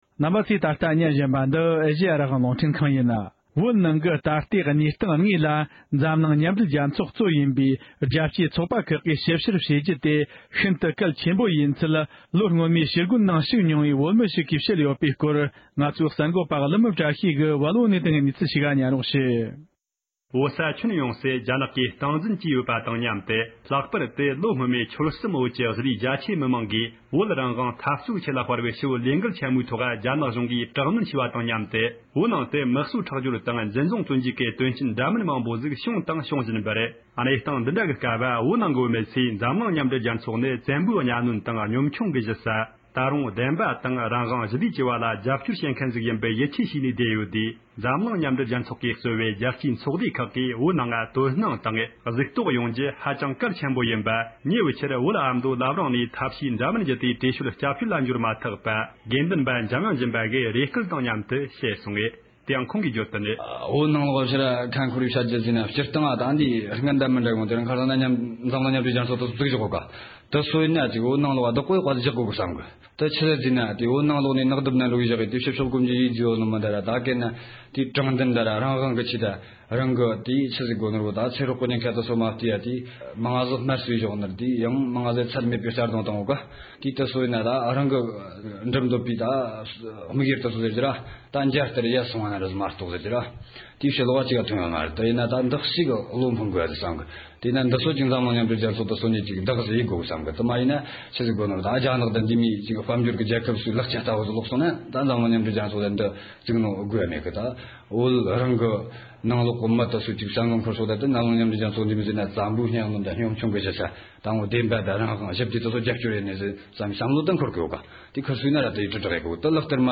ན་ནིང་བོད་མིའི་ཞི་རྒོལ་ནང་མཉམ་ཞུགས་གནང་མྱོང་མཁན་གྱི་བོད་མི་ཞིག་གིས་བོད་ནང་གི་ད་ལྟའི་གནས་སྟངས་ངོ་སྤྲོད་གནང་བ།
སྒྲ་ལྡན་གསར་འགྱུར།